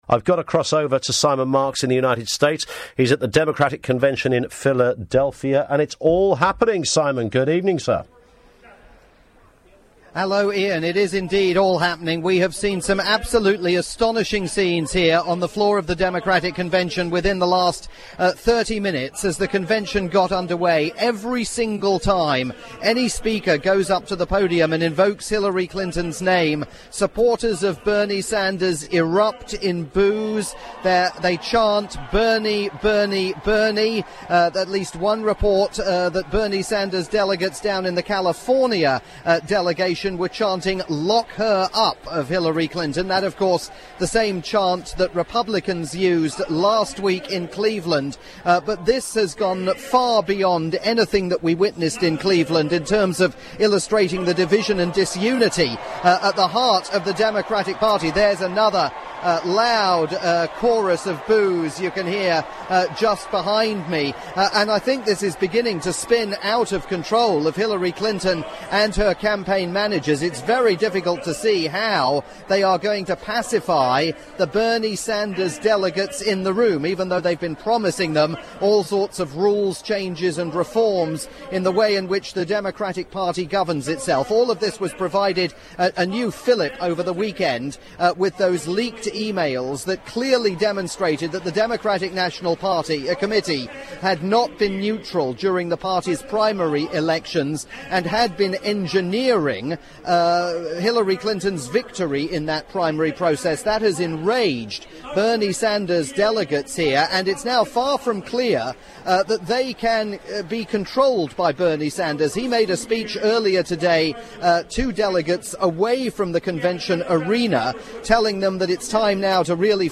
July 25, 2016 - AS IT BROKE: Uproar on the floor of the Democratic Convention